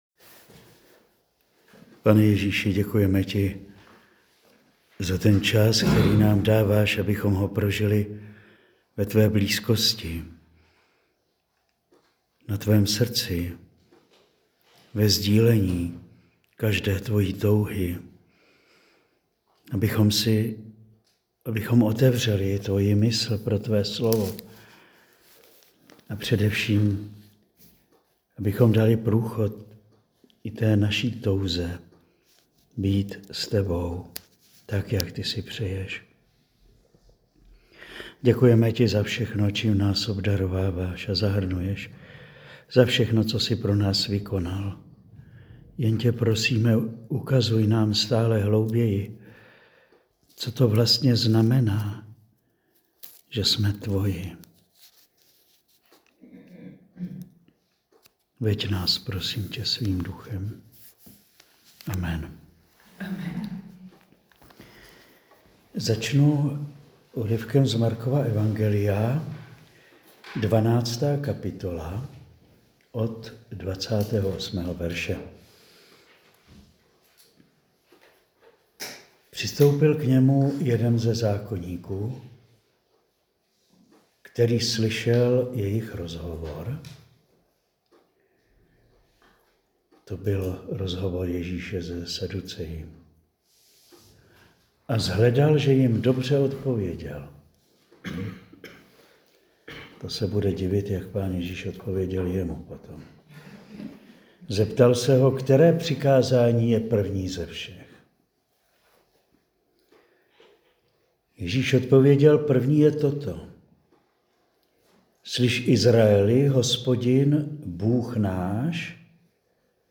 Přednáška zazněla při duchovní obnově pro sestry karmelitky v Dačicích dne 9. 3. 2025